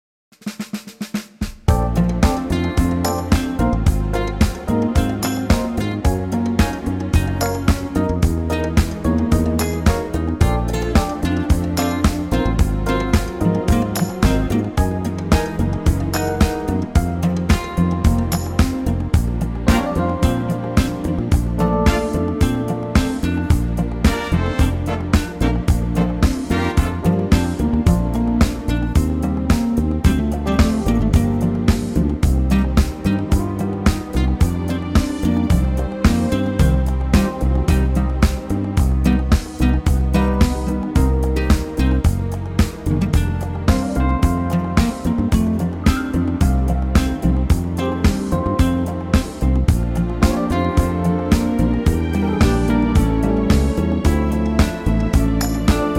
key - Bb - vocal range - A to C